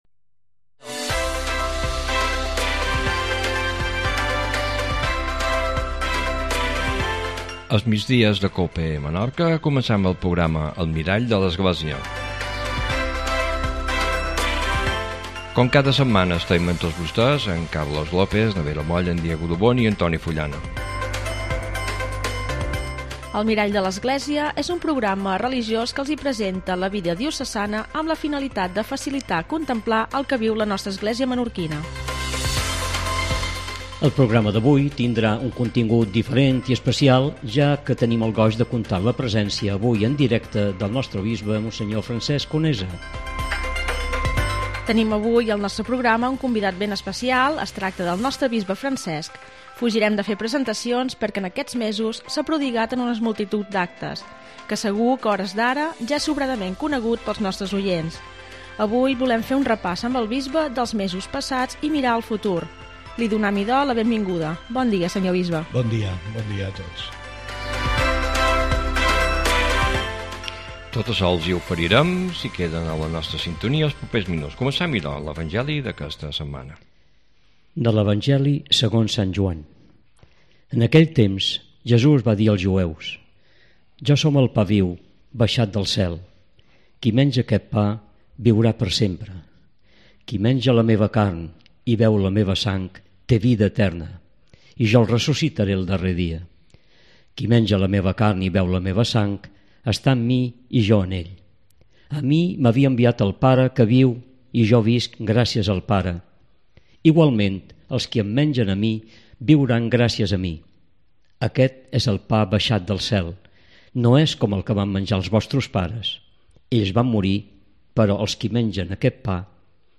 Entrevista al Bisbe Francesc Conesa.